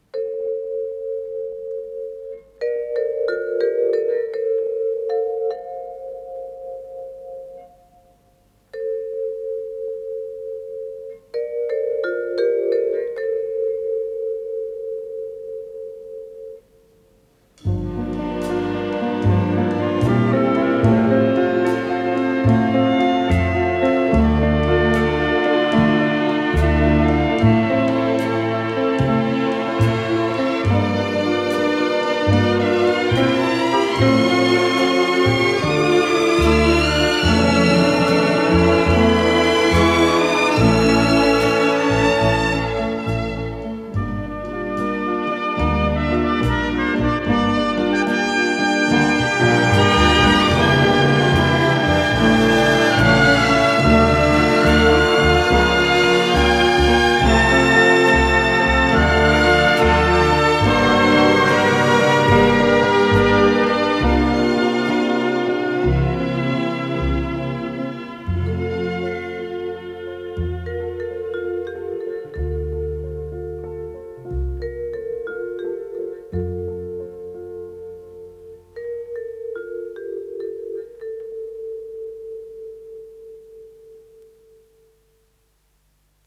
ПодзаголовокЗаставка, фа мажор
ВариантДубль моно